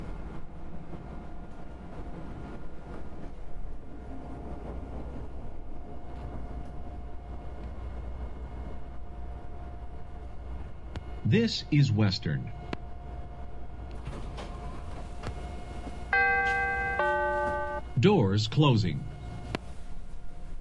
在布鲁塞尔的地铁里 " Métro en attente
描述：进入地铁车厢，等待，车门关闭，地铁启动，到达下一站，车门打开。用小立体声微型机录制，在迷你碟上。
标签： 儿童 关闭警报 关门 地铁 电机 噪音小 启动 声音
声道立体声